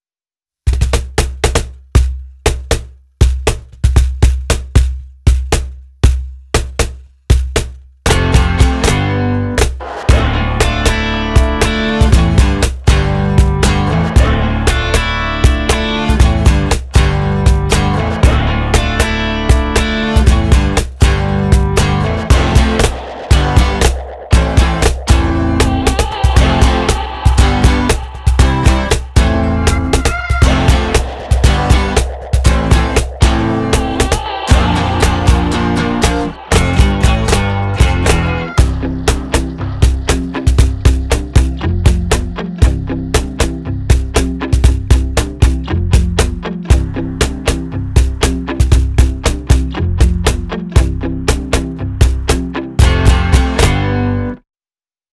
Feature 1: Three Piezo pickups for snare and bass sounds Feature 2: Baltic birch construction Feature 3: Dual internal fixed snare wires Feature 4: R…